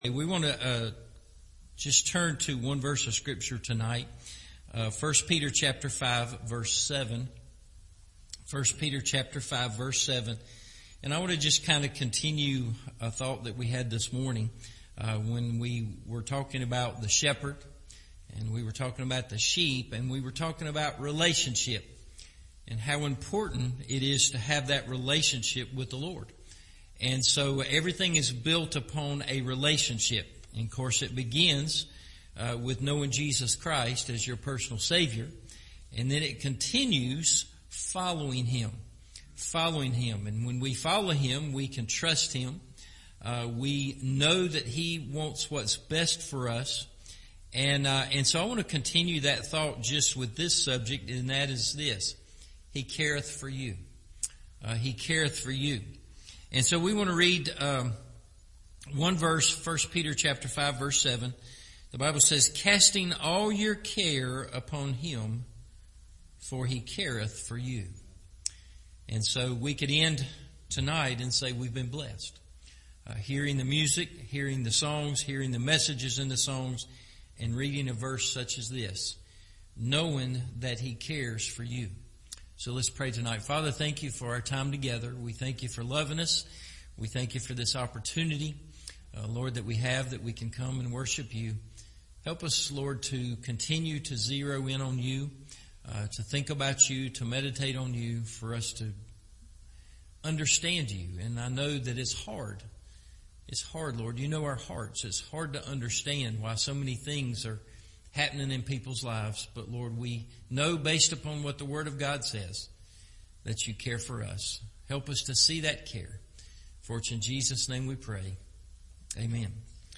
He Careth For You – Evening Service